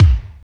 25.10 KICK.wav